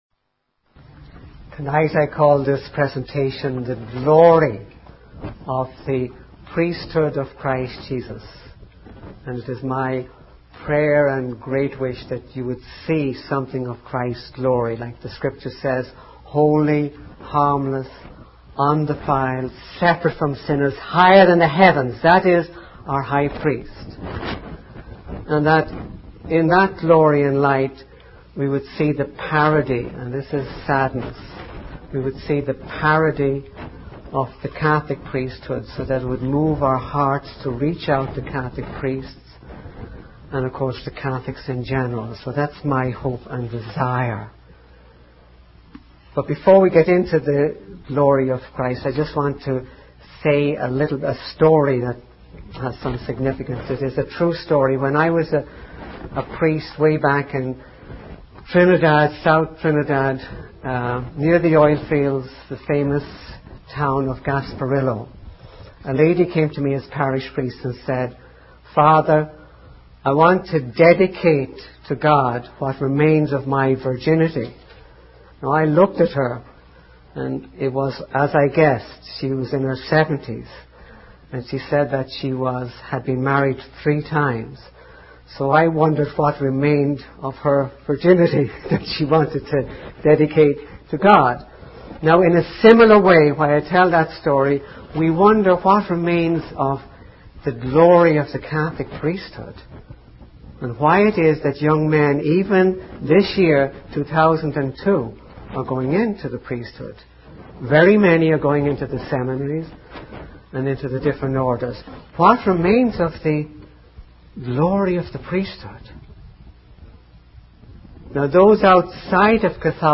In this sermon, the speaker discusses the corruption and issues within American seminaries and the Catholic Church. They highlight the prevalence of doctrinal error, liturgical abuse, and misconduct among seminarians. The speaker emphasizes the importance of recognizing the glory of Christ's priesthood and the need to reach out to Catholic priests and believers.